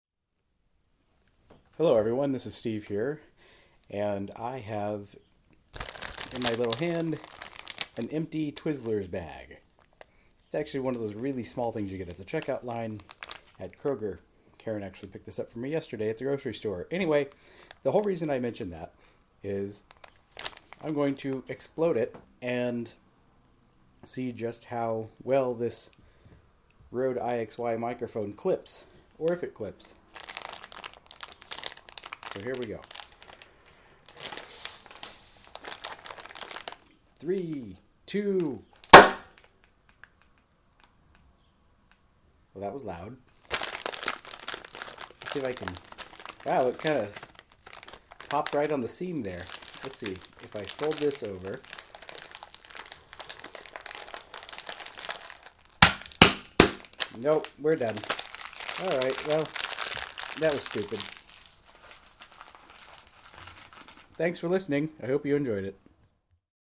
Let's find out whether popping an empty bag of Twizzlers overloads the Rode iXY microphone. Fair warning: it's loud!